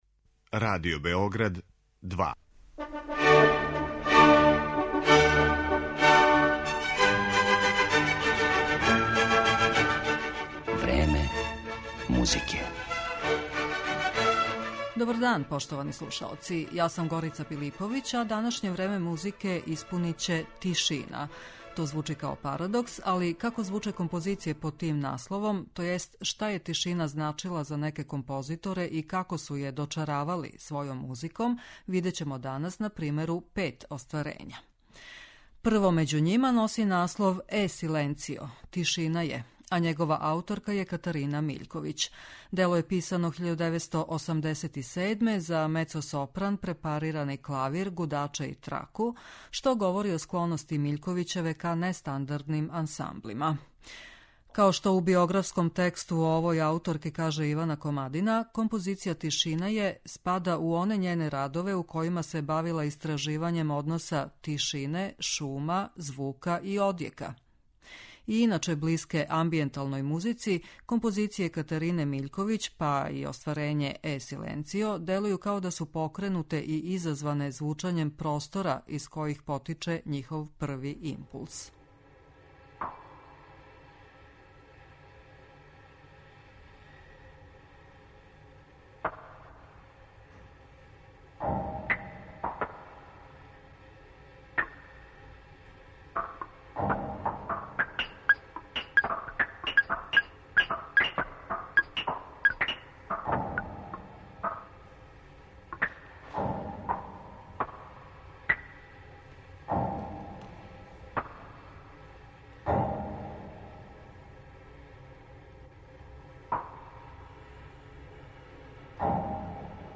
Дело је писано 1987. за мецосопран, препарирани клавир, гудаче и траку, што говори о склоности Миљковићеве ка нестандардним ансамблима.